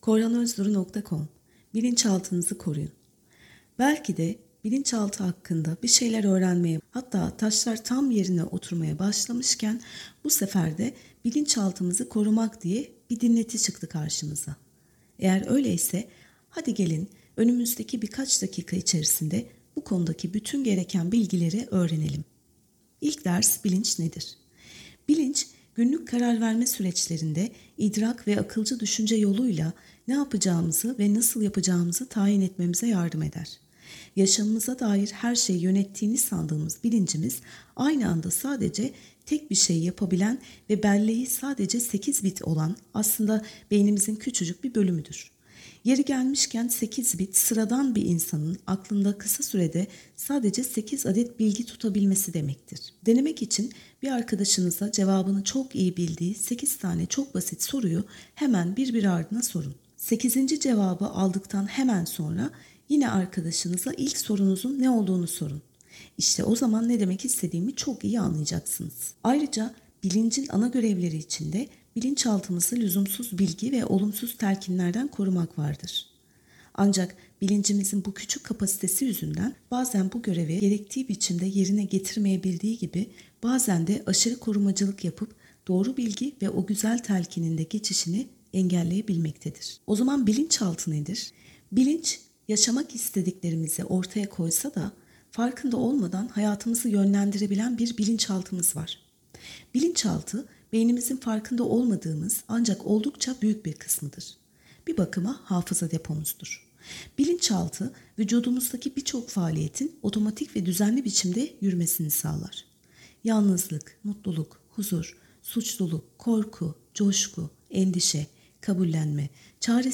BilincaltiniziKoruyunSesliOkuma.mp3